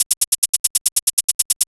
Techno / Drum / HIHAT021_TEKNO_140_X_SC2.wav